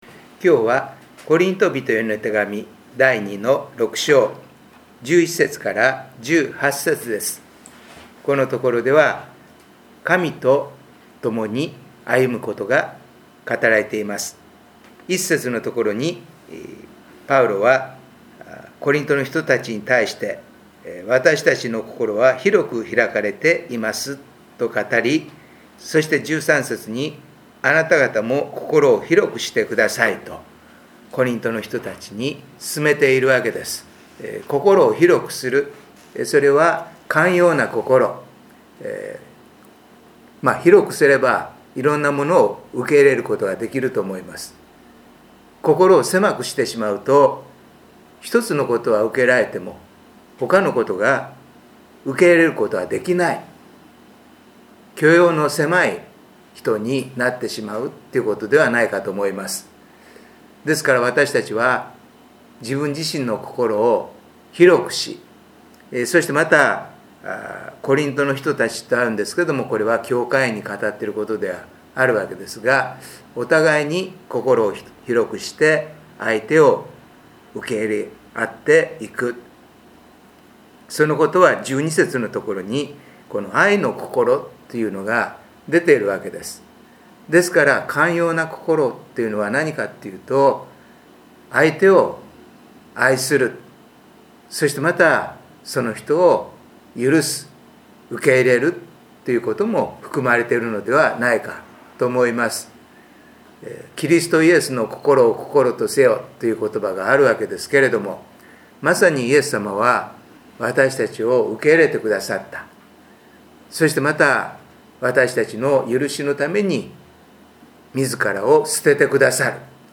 音声メッセージです。